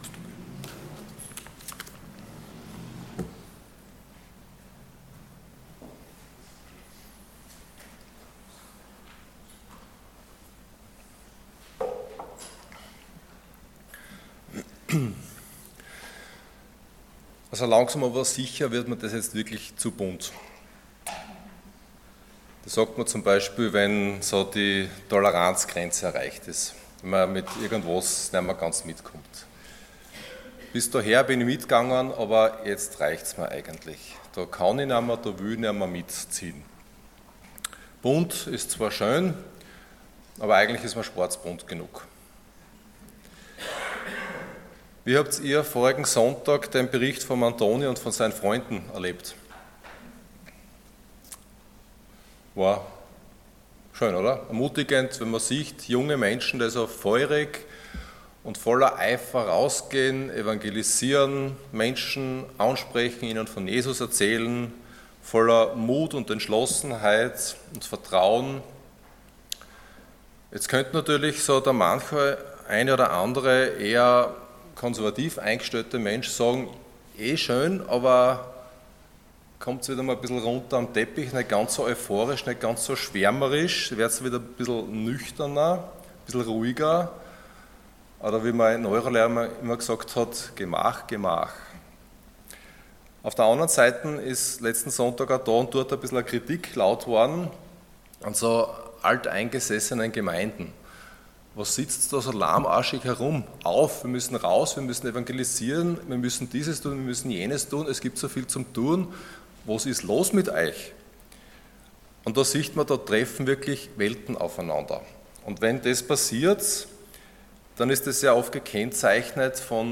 Passage: Römer15,1-13 Dienstart: Sonntag Morgen